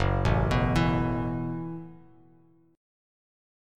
F#m11 chord